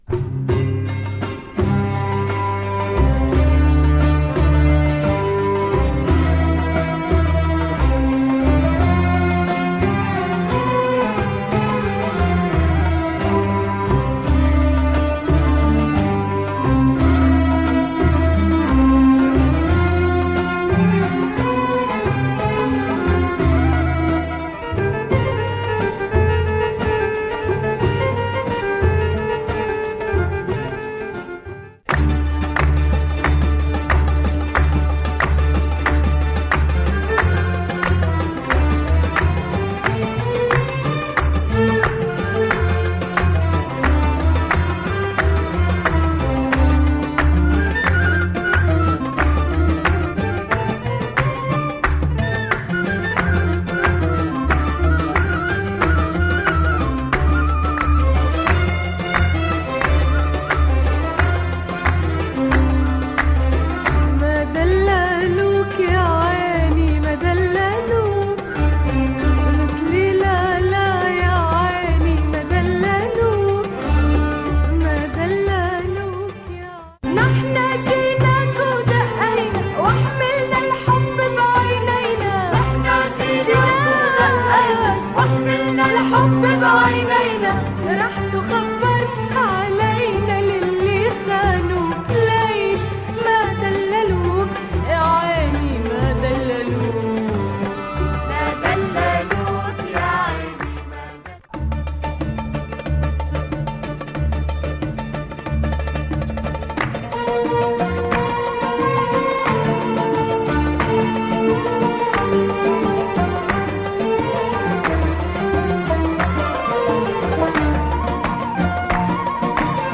Oriental beats and beautiful melodies